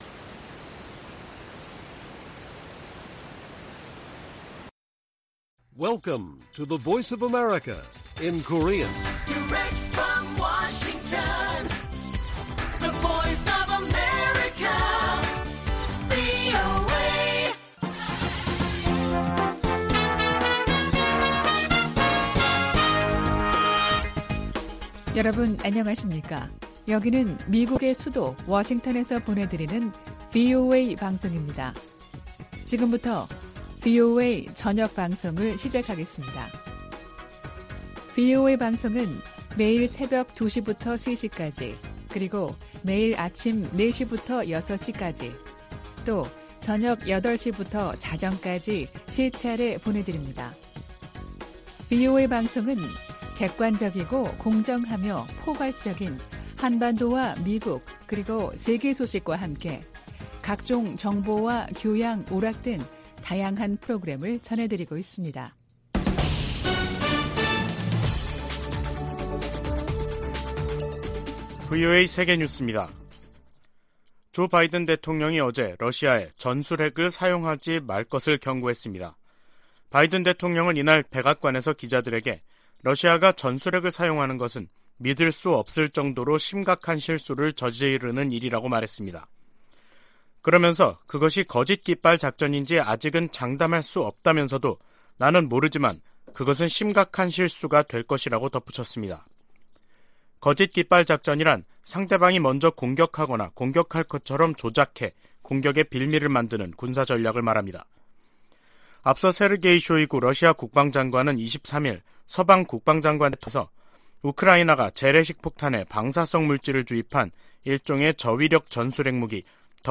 VOA 한국어 간판 뉴스 프로그램 '뉴스 투데이', 2022년 10월 26일 1부 방송입니다. 미국·한국·일본의 외교차관이 26일 도쿄에서 협의회를 열고 핵실험 등 북한의 추가 도발 중단을 강력히 촉구했습니다. 미 국무부는 북한이 7차 핵실험을 강행할 경우 대가를 치를 것이라고 경고한 사실을 다시 강조했습니다.